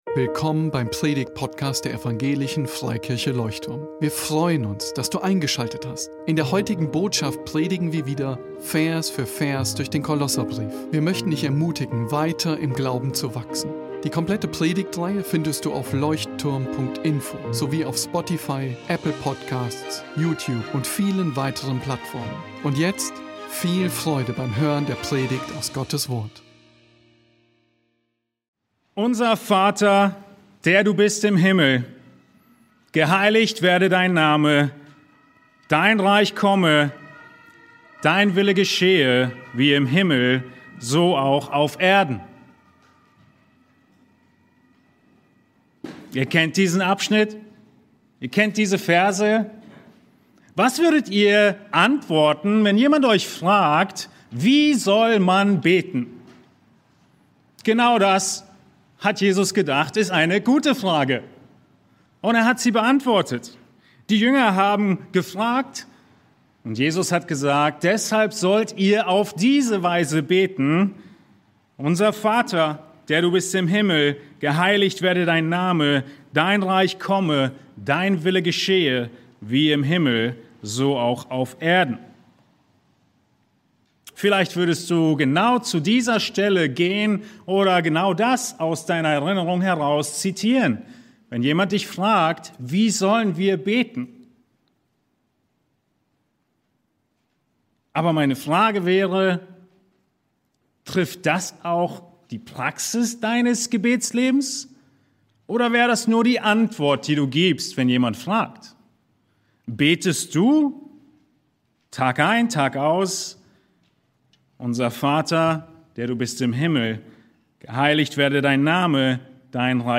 Predigtpodcast